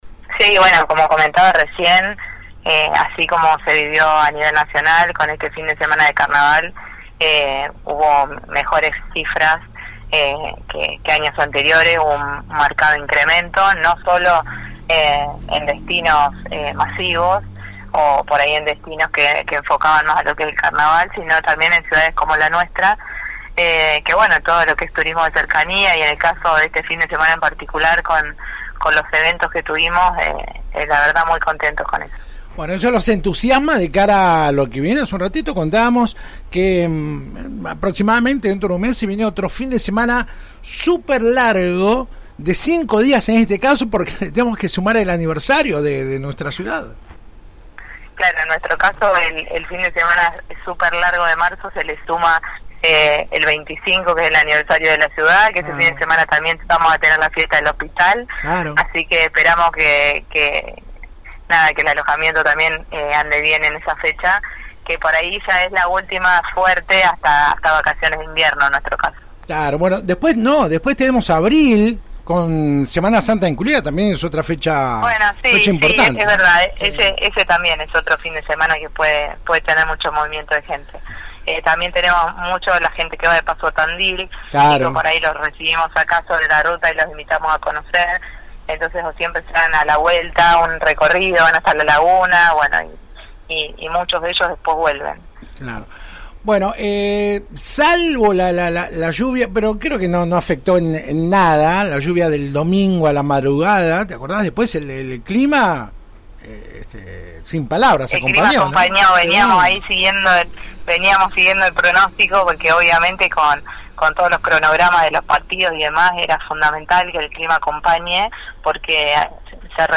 La directora de Turismo y Promoción local, Wanda Borda, se refirió este miércoles en la 91.5 al fin de semana extra largo que dejó cifras récord en diversos puntos de la provincia como así también en nuestra ciudad.